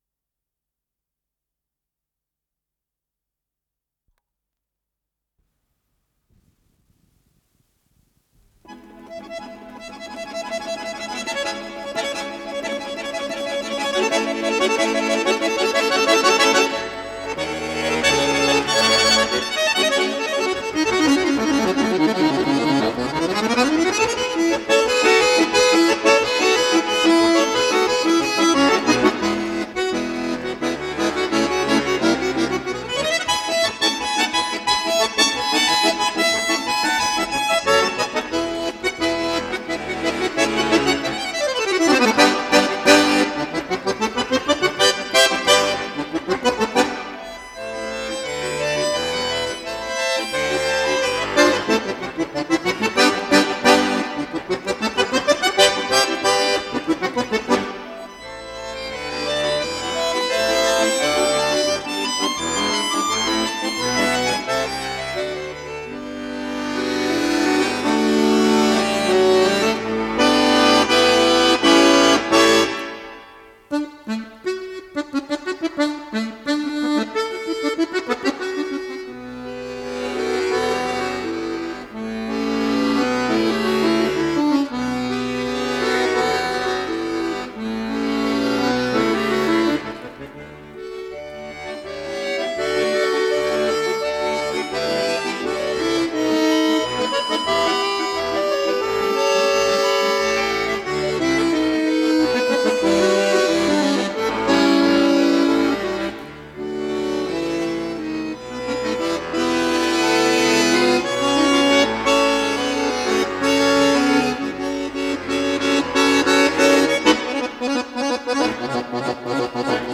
баян